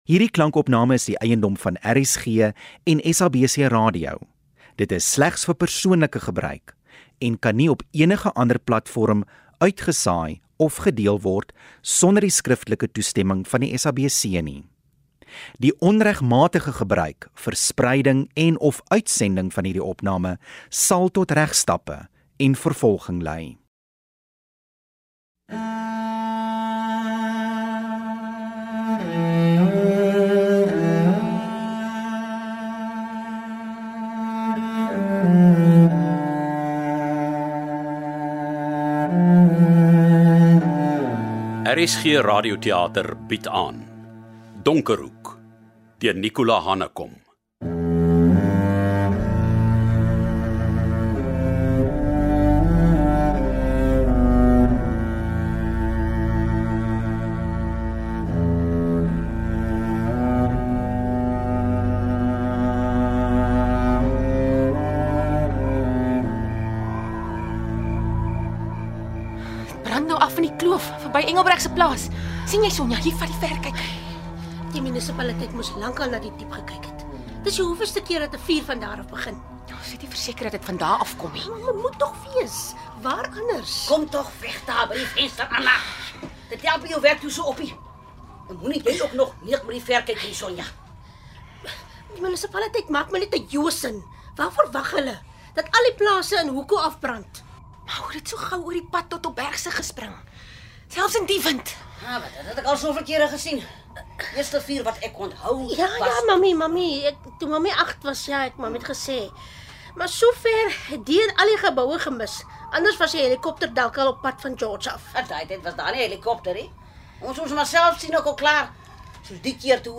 Waarskuwing: Nie vir sensitiewe luisteraars aanbeveel nie, weens taalgebruik en volwasse temas. https